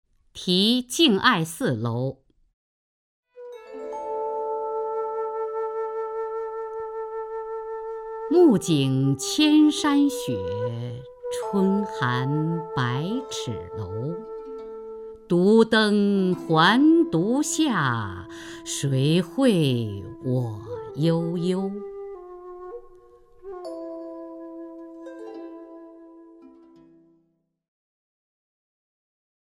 雅坤朗诵：《题敬爱寺楼》(（唐）杜牧) （唐）杜牧 名家朗诵欣赏雅坤 语文PLUS